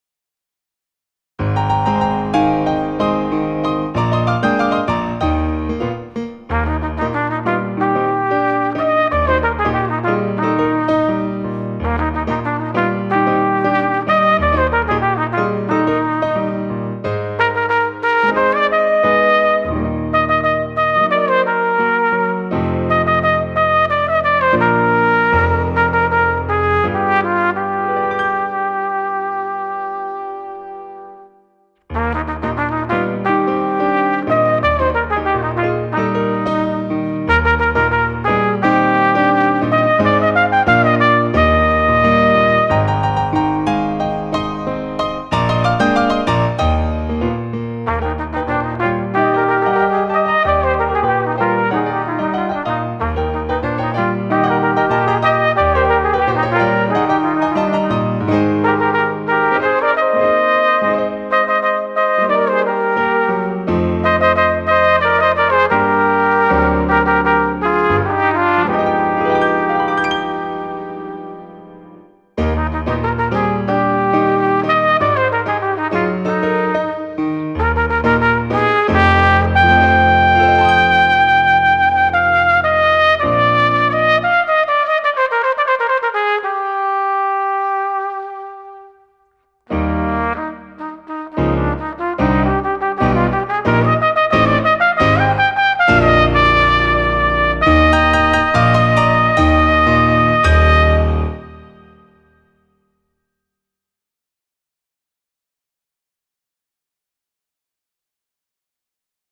0208-小号名曲阿拉木汗.mp3